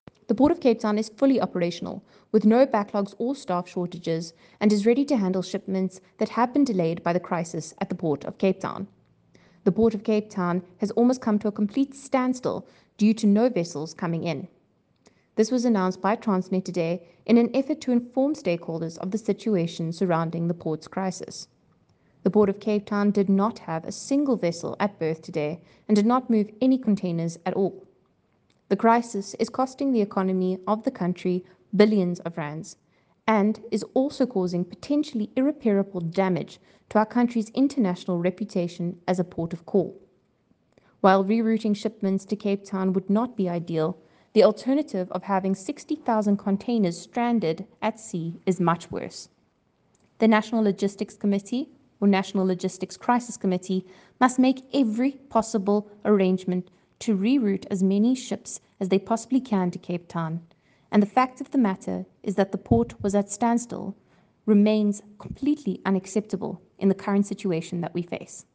English soundbite from MPP Cayla Murray attached.